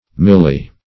Milli- \Mil"li-\ (m[i^]l"l[i^]-).